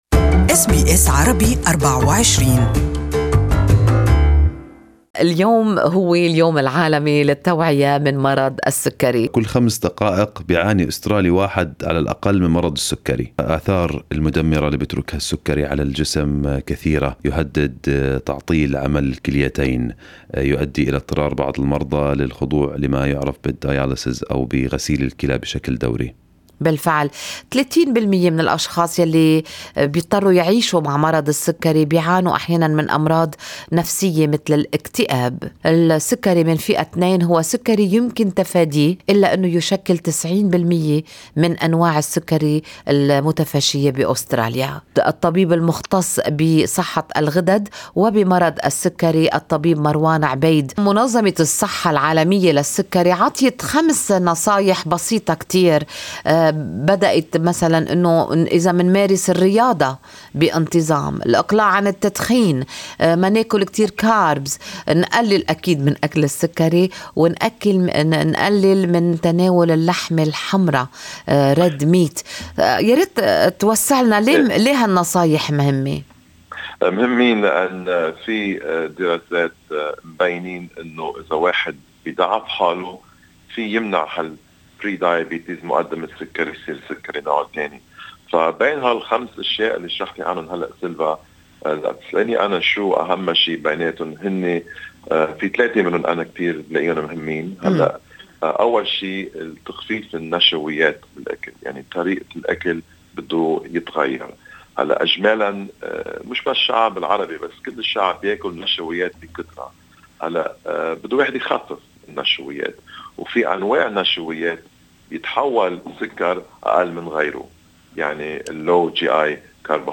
المقابلة كاملة مع الطبيب مرفقة بالصورة أعلاه.